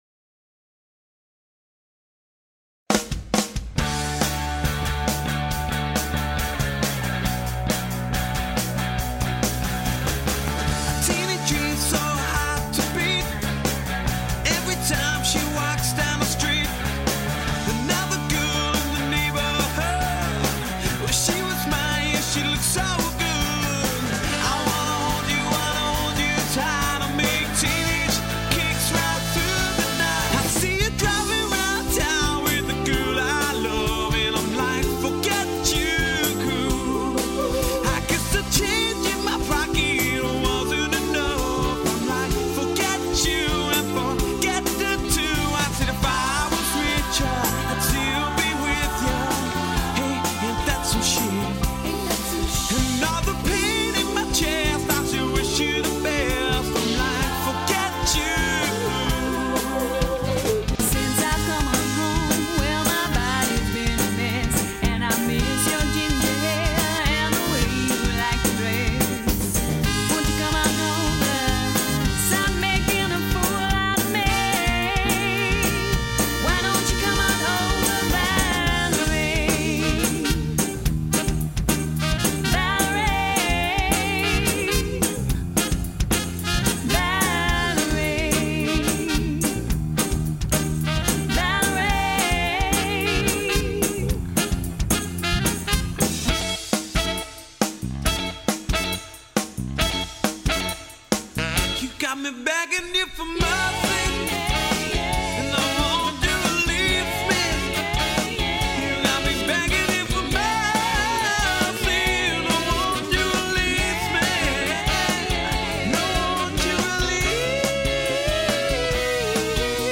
an energetic Party Band